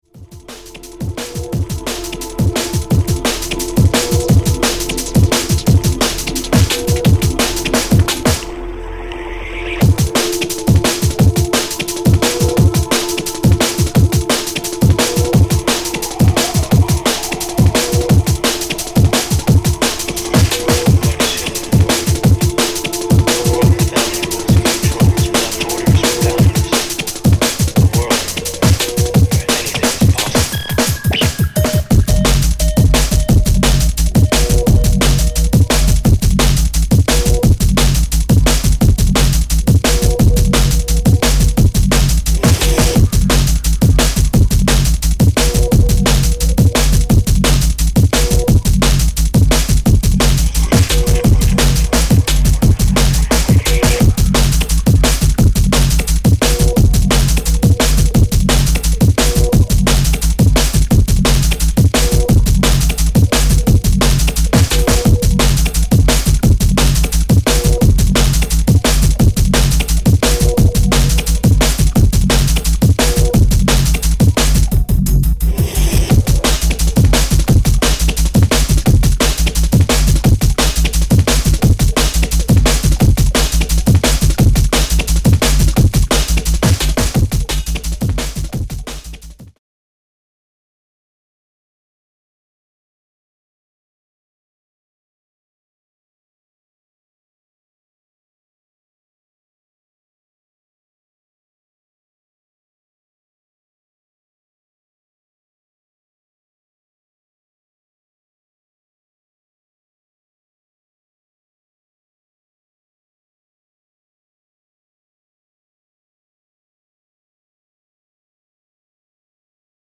Jungle/Drum n Bass
Jump Up